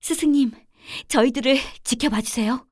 cleric_f_voc_social_05.wav